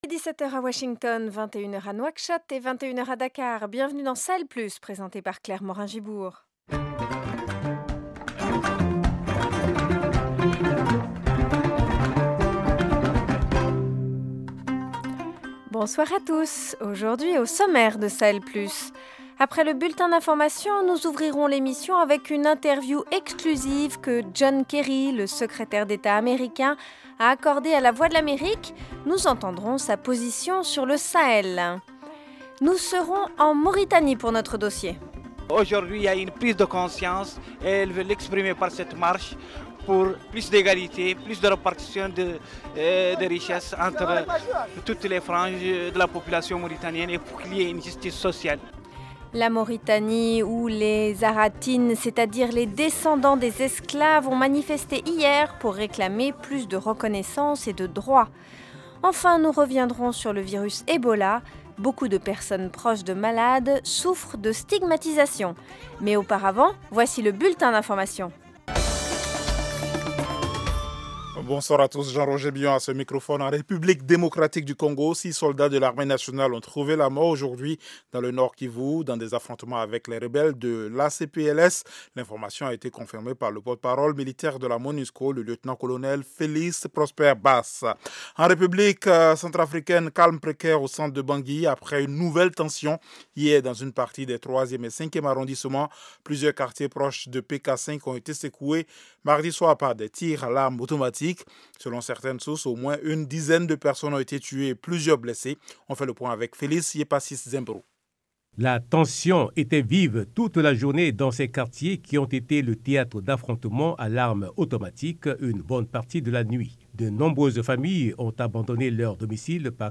Au programme : interview exclusive en français de John Kerry, Secrétaire d’Etat américain. Quels sont les défis sécuritaires du Sahel et quelle aide les Etats-Unis peuvent-ils apporter ? Dossier : les haratines ou descendants des esclaves ont manifesté hier en Mauritanie pour réclamer plus de reconnaissance et de droits.